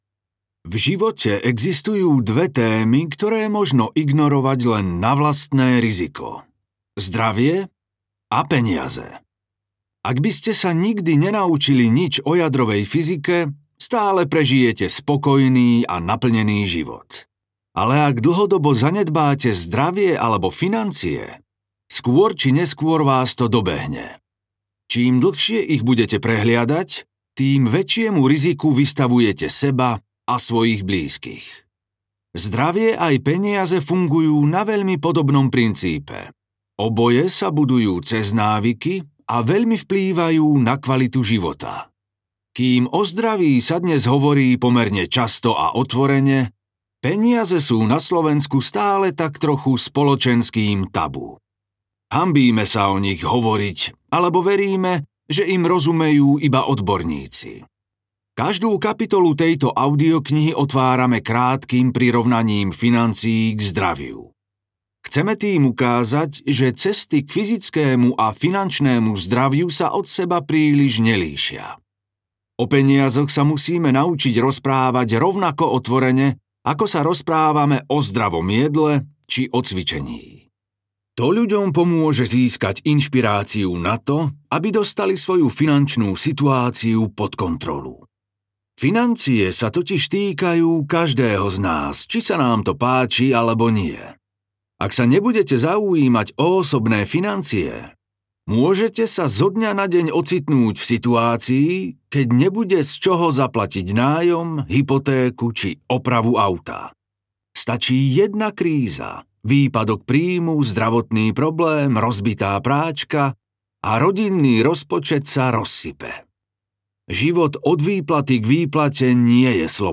Koľko stojí vaše šťastie? audiokniha
Ukázka z knihy
kolko-stoji-vase-stastie-audiokniha